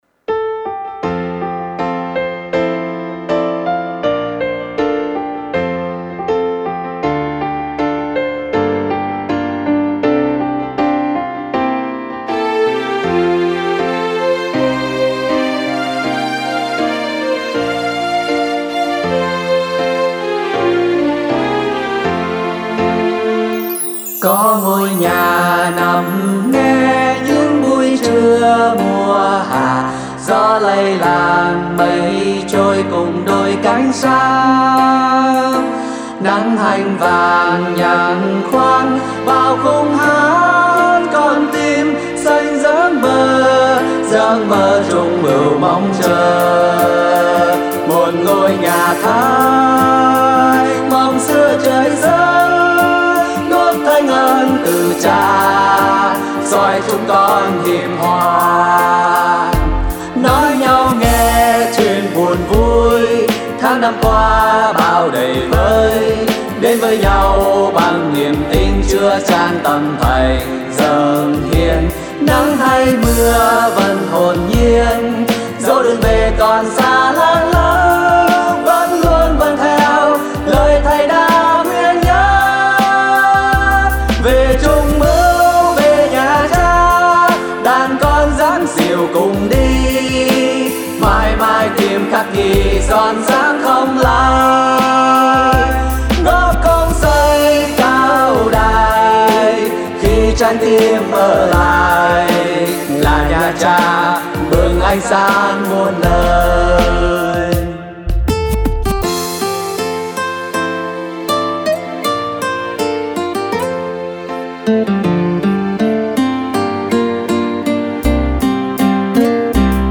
Thể Loại Đạo Ca
Tốp (F)